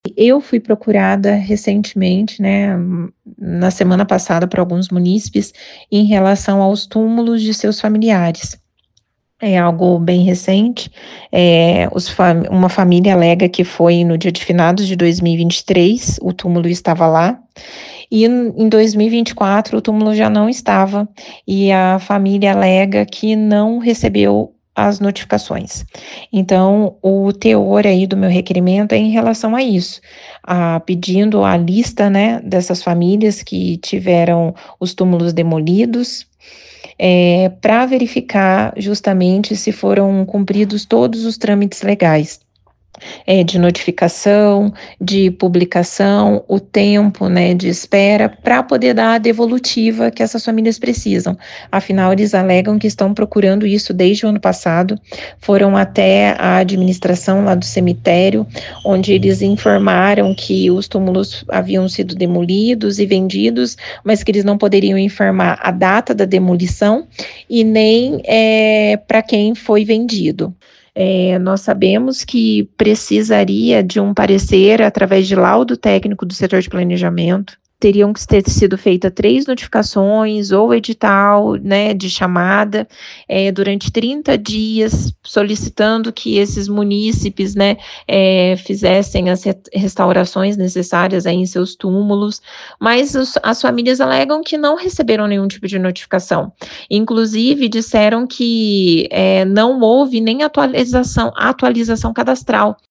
A vereadora Professora Hellen (PODE) concedeu uma entrevista exclusiva à 92FM São João, na qual abordou a polêmica sobre a suposta venda irregular de túmulos no Cemitério de São João da Boa Vista.